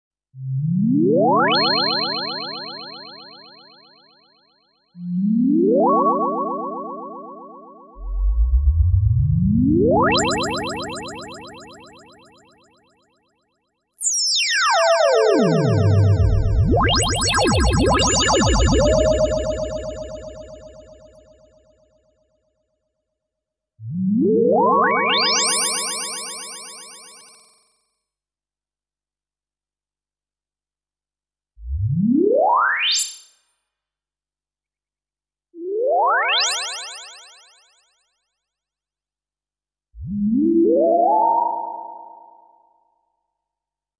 SFX " 科学幻想的变化
描述：5/5 具有延迟的复古声音经线/低音科幻音效。
Tag: 水下 复古 电子 效果 数字 声音效果 未来 外汇 延迟 上升 经编 woosh 合成器 向上 电视 空间 SFX 科幻 魔术 soundesign